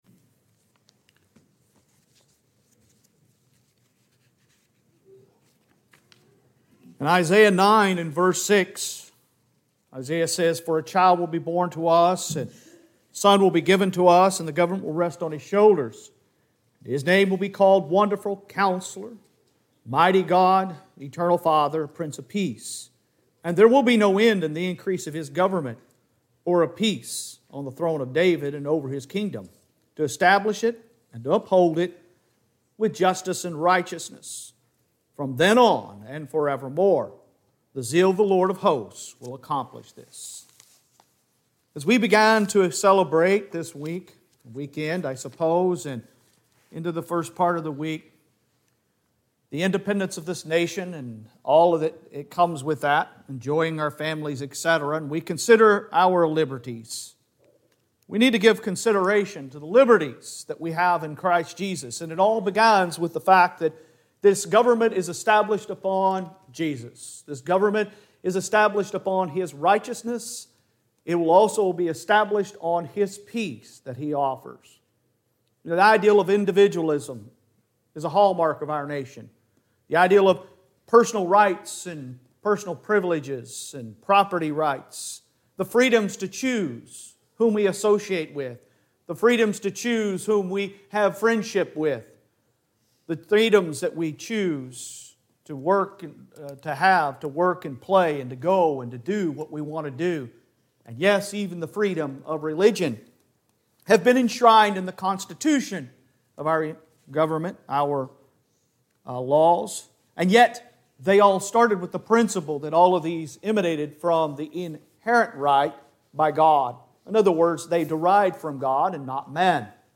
Sun AM Sermon 07.03.22